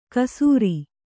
♪ kasūri